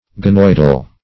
ganoidal - definition of ganoidal - synonyms, pronunciation, spelling from Free Dictionary Search Result for " ganoidal" : The Collaborative International Dictionary of English v.0.48: Ganoidal \Ga*noid"al\, a. (Zool.)